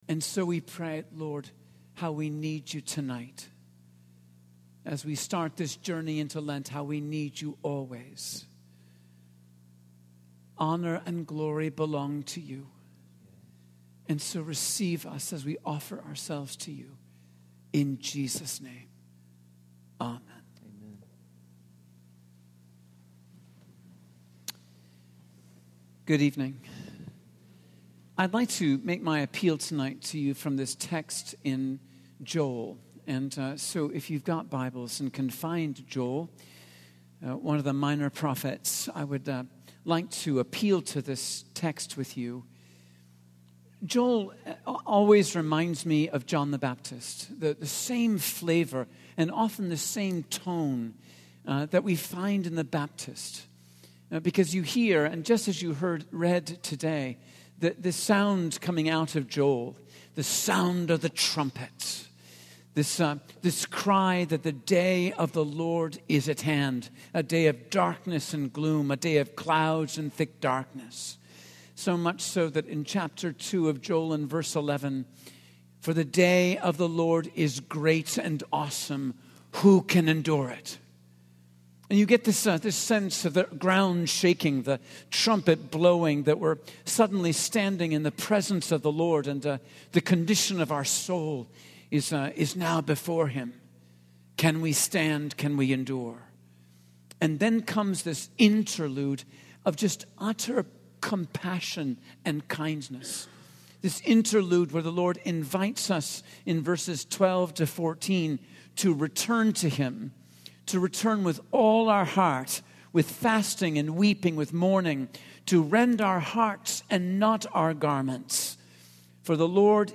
In this sermon, the speaker emphasizes the importance of submitting to a process for the well-being of the soul.